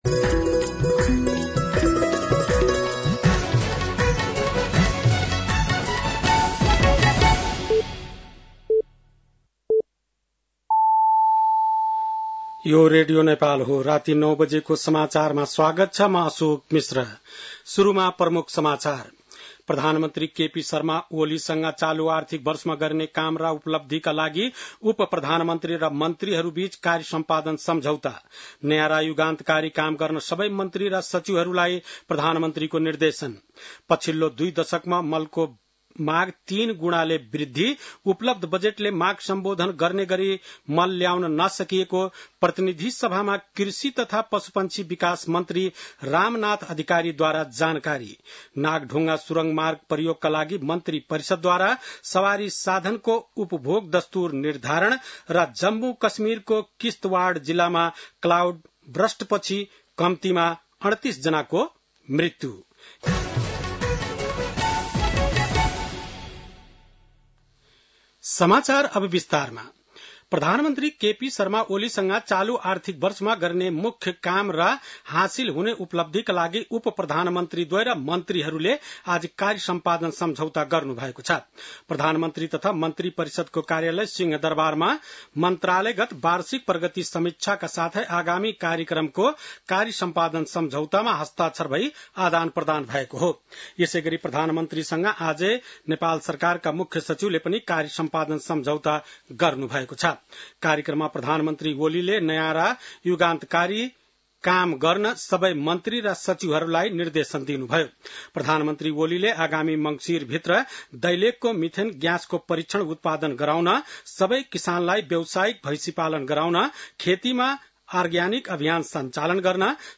बेलुकी ९ बजेको नेपाली समाचार : २९ साउन , २०८२
9-PM-Nepali-NEWS-1-2.mp3